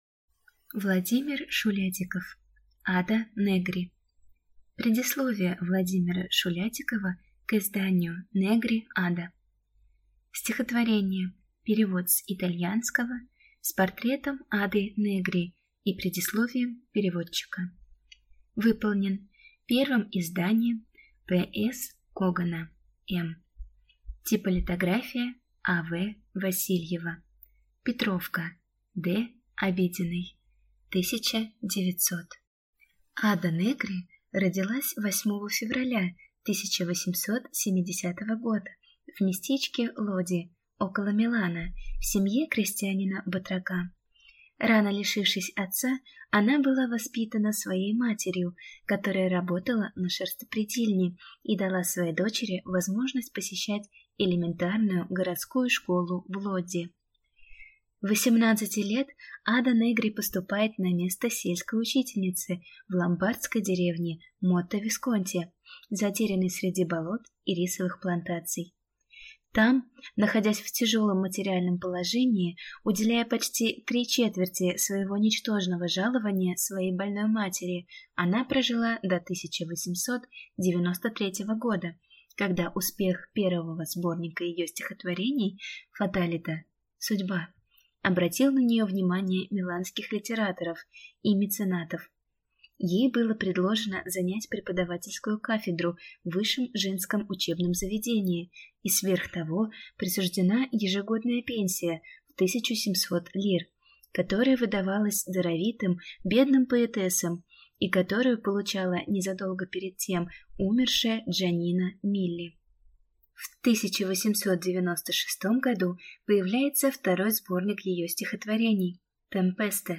Аудиокнига Ада Негри | Библиотека аудиокниг